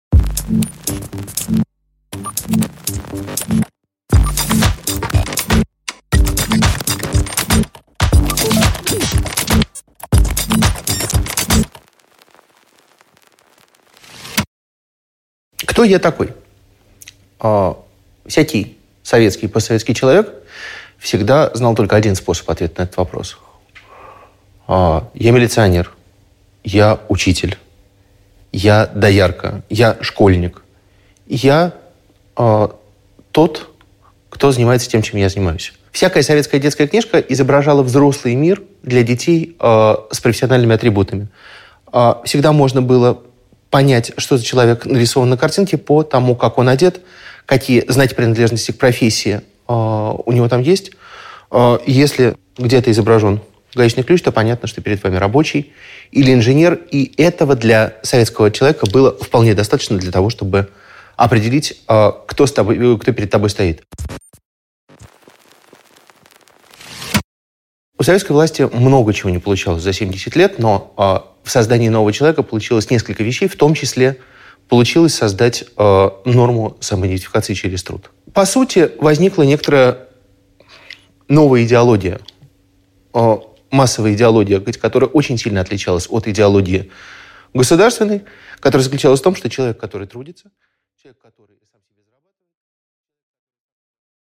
Аудиокнига Дело жизни: новое отношение к труду | Библиотека аудиокниг